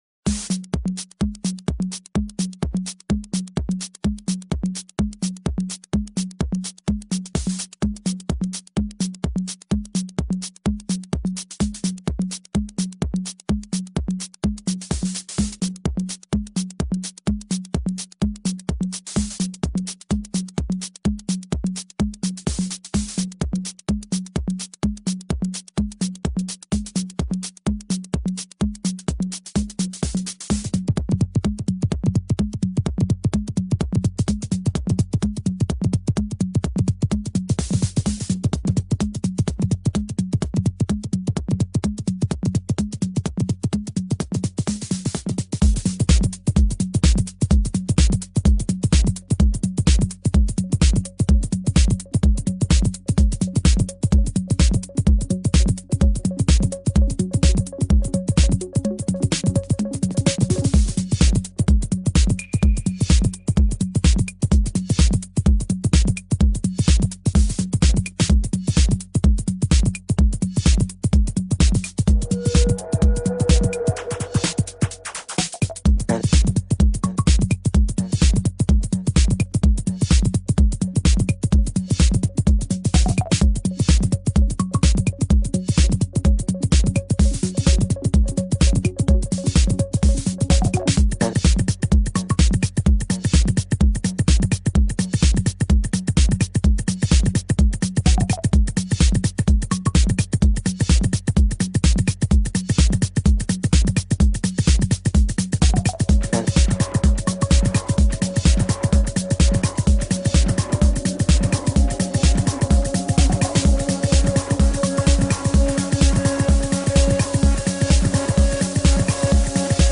trance
reconocible por sus espesas líneas de bajos